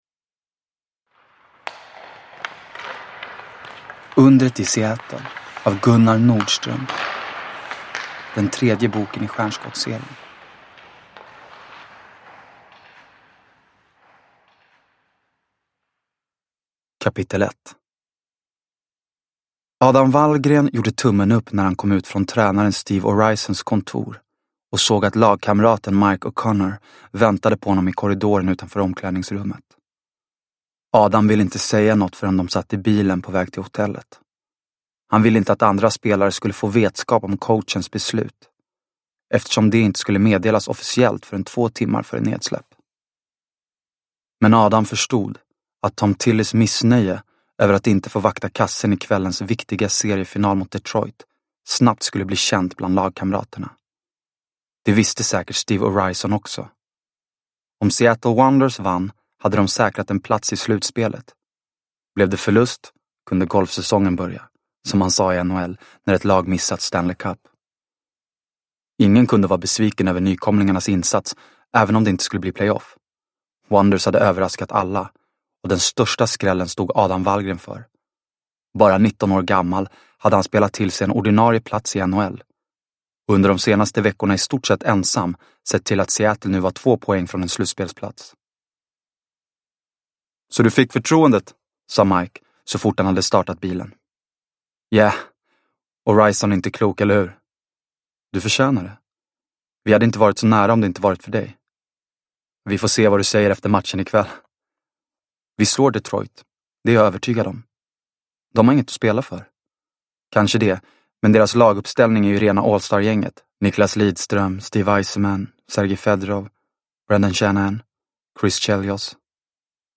Undret i Seattle – Ljudbok – Laddas ner